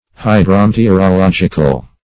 Search Result for " hydrometeorological" : The Collaborative International Dictionary of English v.0.48: Hydrometeorological \Hy`dro*me`te*or`o*log"ic*al\, a. Of or pertaining to hydrometeorology, or to rain, clouds, storms, etc. [1913 Webster]